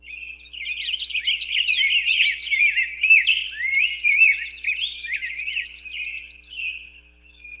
Forest3.mp3